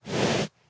horse
breathe1.ogg